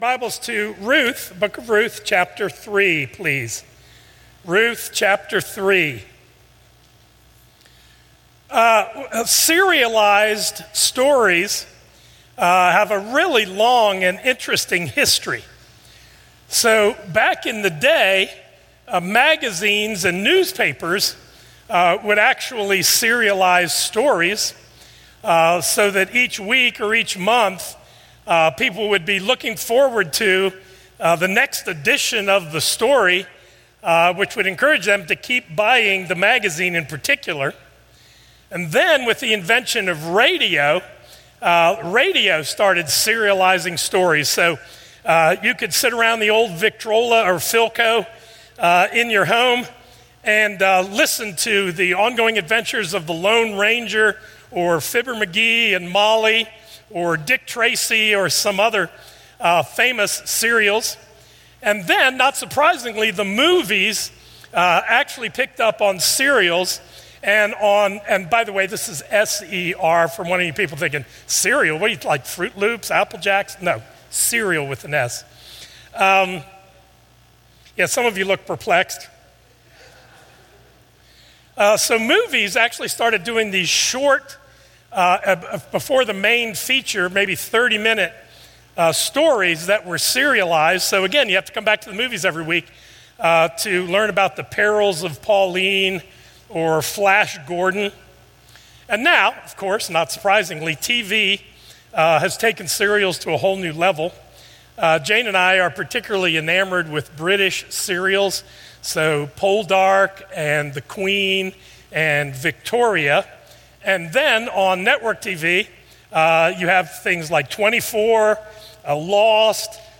A message from the series "Behold Him."